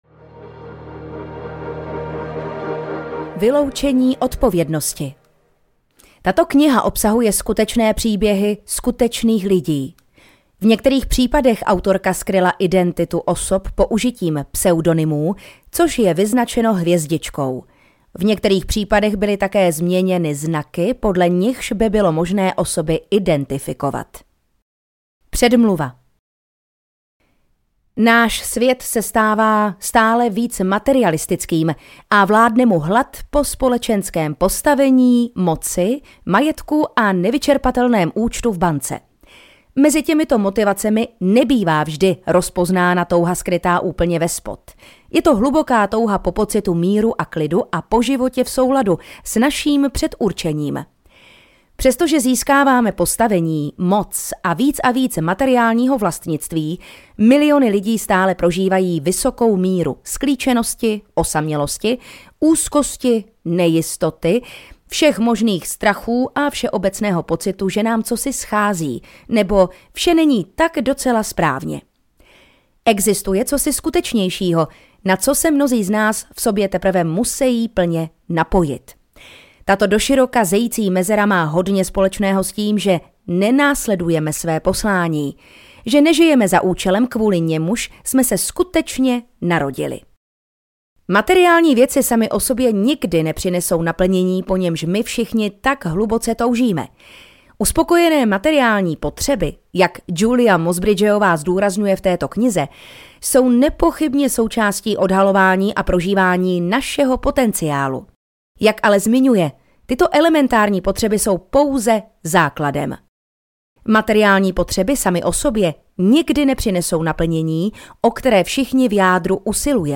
Poslání audiokniha
Ukázka z knihy